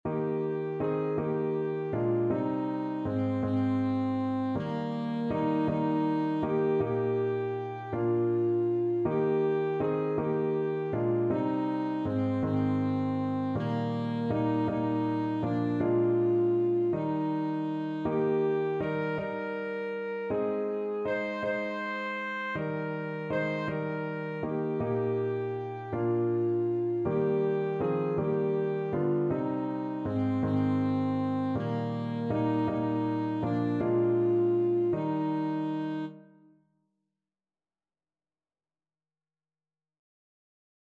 Alto Saxophone
6/4 (View more 6/4 Music)
Classical (View more Classical Saxophone Music)